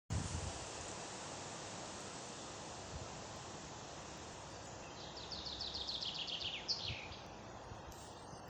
Žubīte, Fringilla coelebs
Ziņotāja saglabāts vietas nosaukumsRojas kapi
StatussDzied ligzdošanai piemērotā biotopā (D)